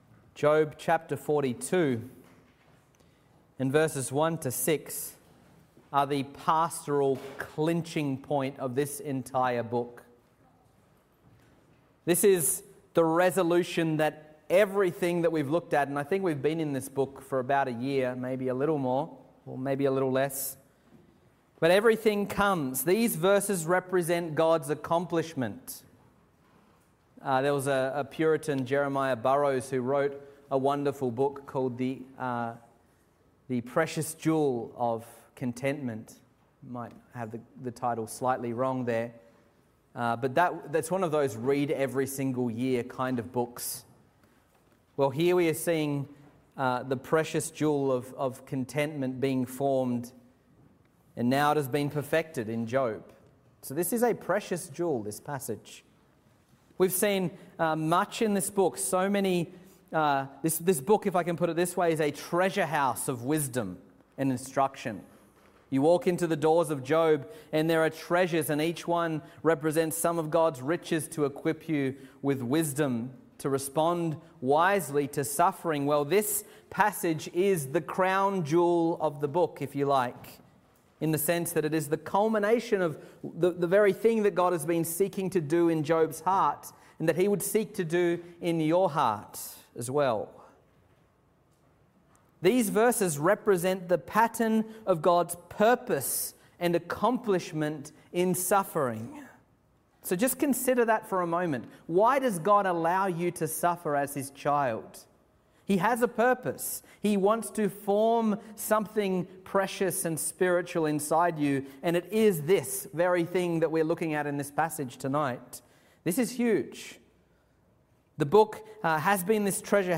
Current Sermon
Evening Service